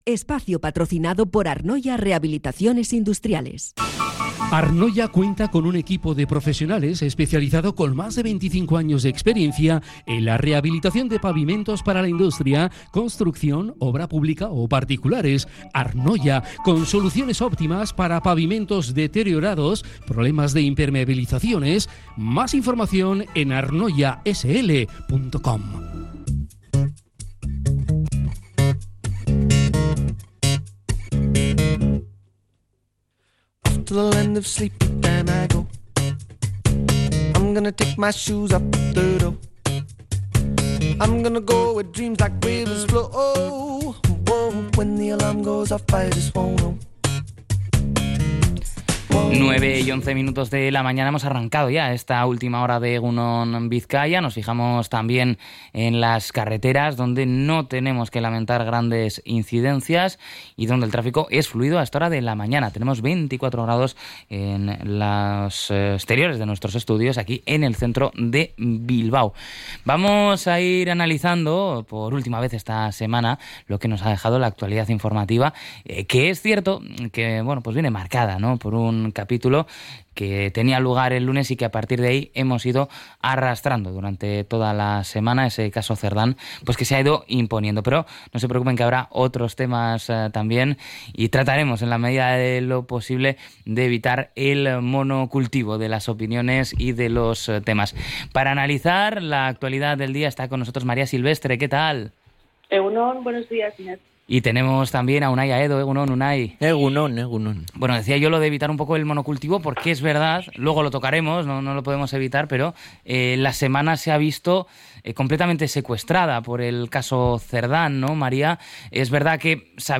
La Tertulia 04-07-25 .